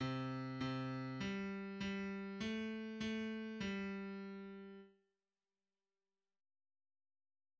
A kotta a Wikipédiába is beágyazható, és a lilypond által előállított midi-file is lejátszható.